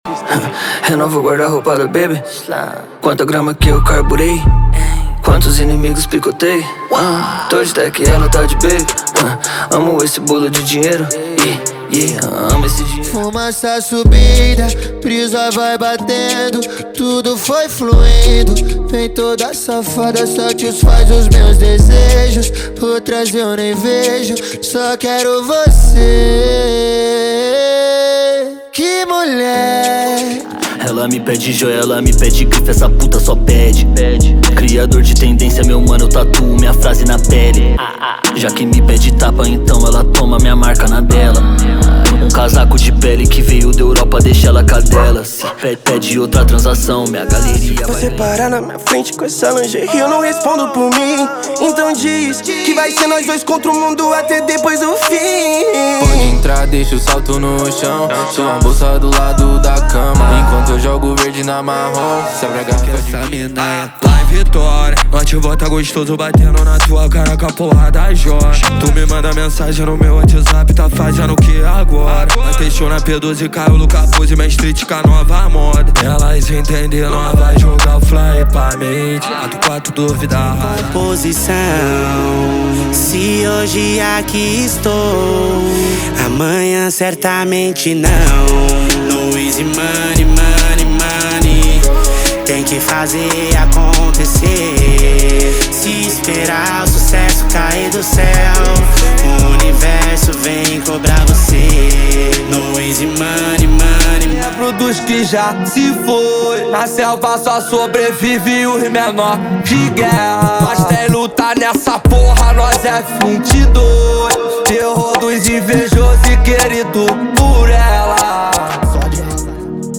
• Rap, Trap Nacional e Funk Ostentação = 100 Músicas
• Sem Vinhetas